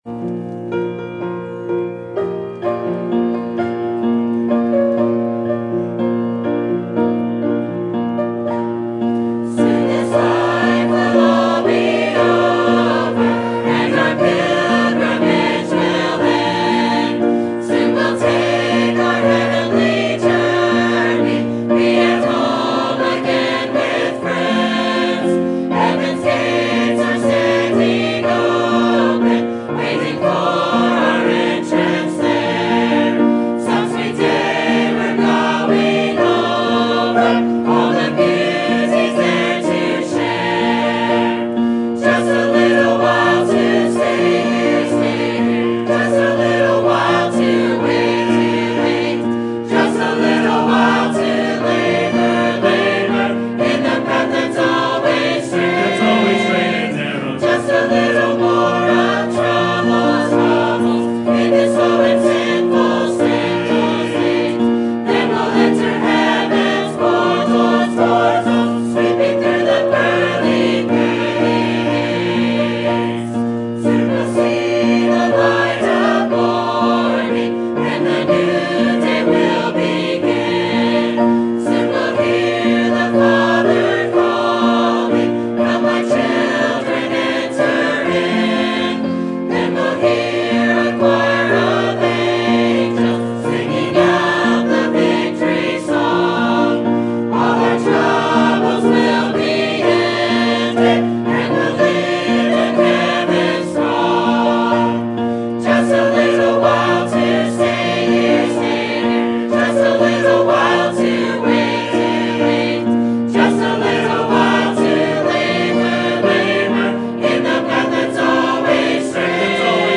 Sermon Topic: General Sermon Type: Service Sermon Audio: Sermon download: Download (28.06 MB) Sermon Tags: Genesis Isaac Famine Response